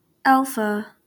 Alpha /ˈælfə/
ALF[1] (uppercase Α, lowercase α)[a] is the first letter of the Greek alphabet.